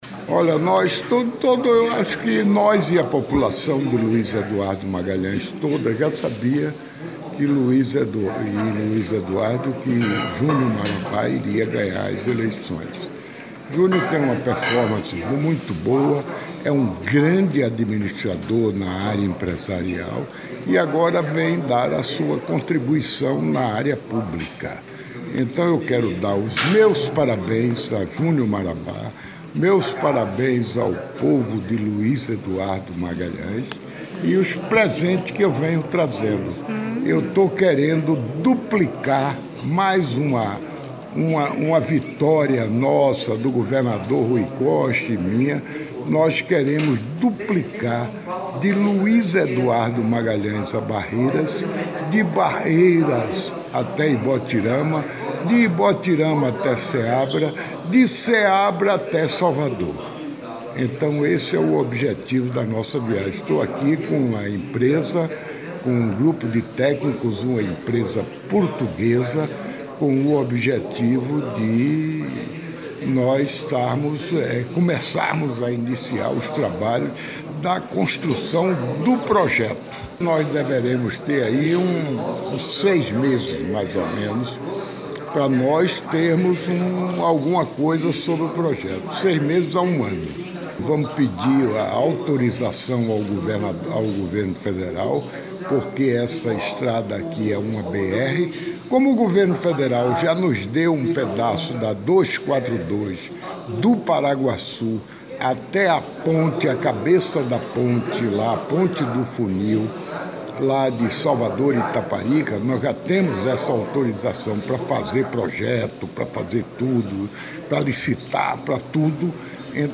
ENTREVISTA
ENTREVISTA OUÇA ENTREVISTA DO VICE-GOVERNADOR JOÃO LEÃO